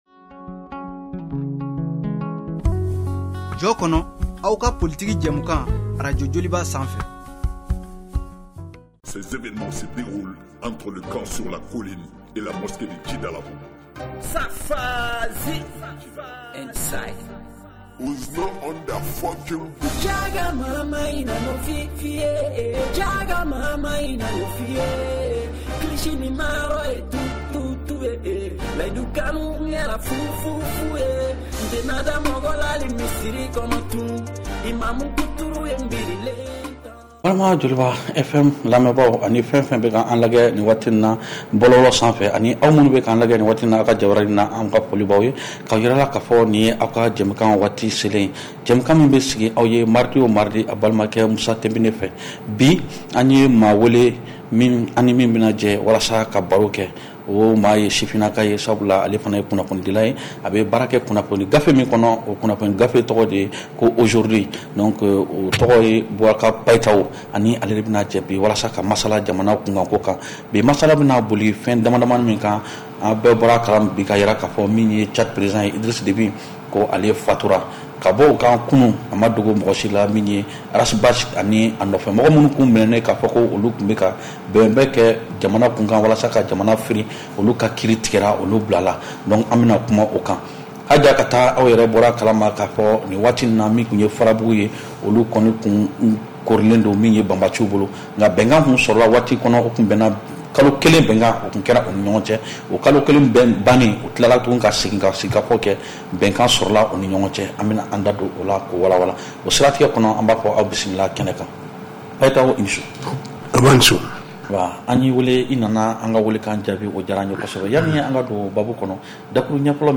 Réécoutez votre émission de débat politique en bambara.